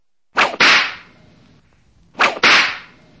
Звуки ударов кнутом
Звук двух жестоких мощных ударов кнутом